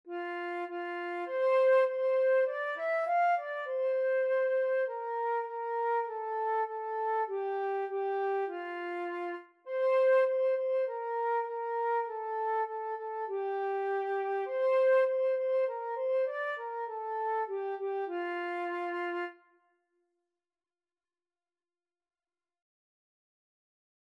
Free Sheet music for Flute
Flute (Chords)
Moderato
4/4 (View more 4/4 Music)
F major (Sounding Pitch) (View more F major Music for Flute )
Traditional (View more Traditional Flute Music)